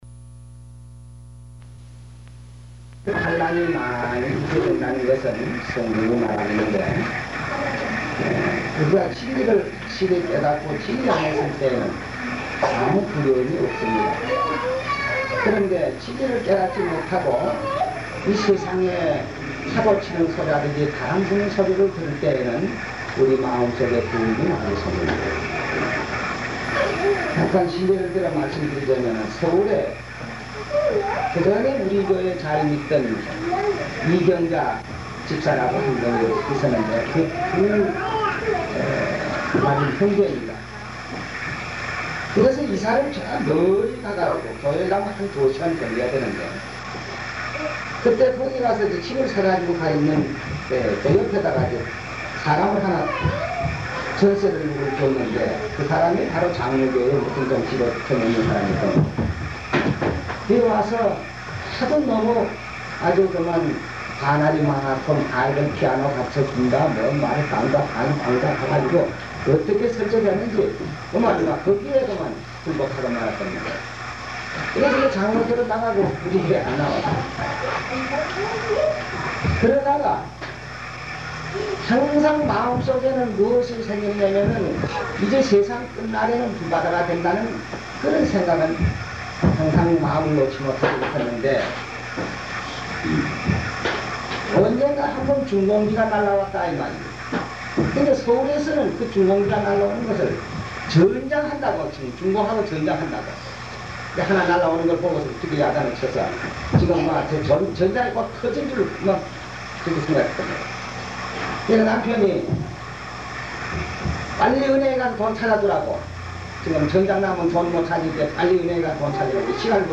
초막절(1984년10월17일 초막절끝날 설교)